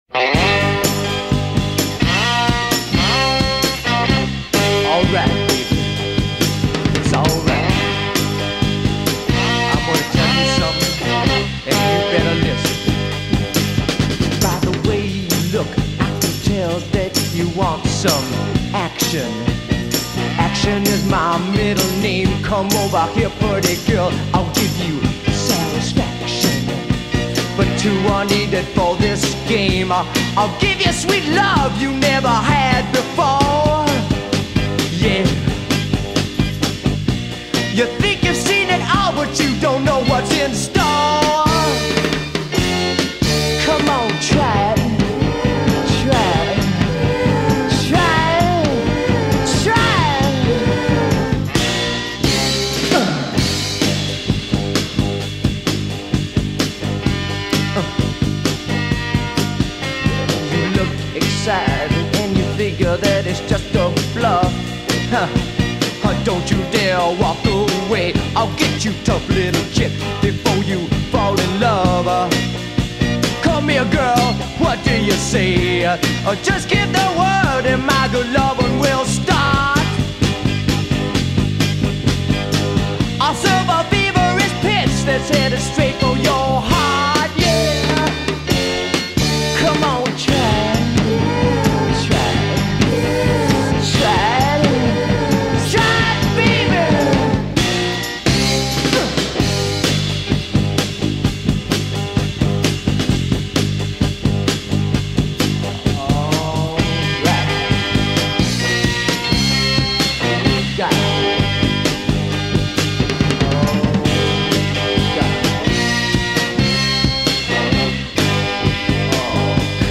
Mono Version